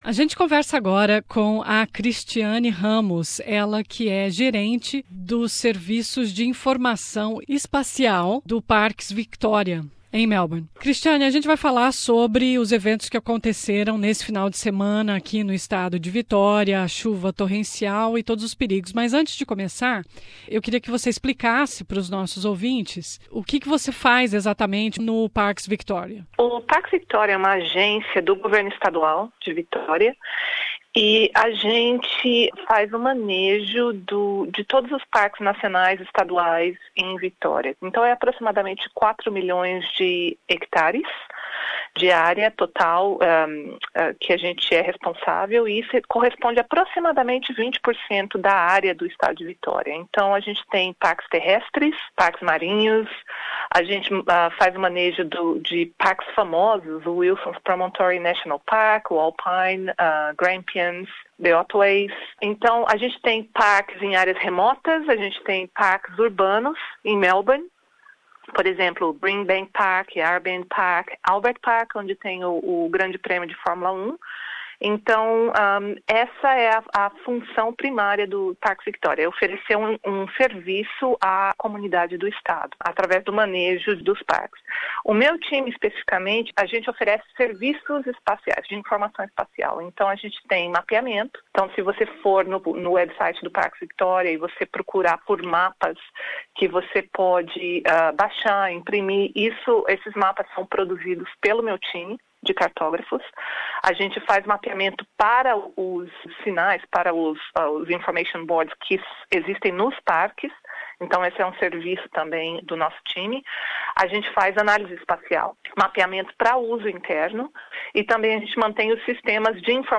Nessa entrevista ela conta como as várias agências operam antes, durante e após eventos climáticos extremos como as chuvas e as enchentes do último final de semana em Vitória.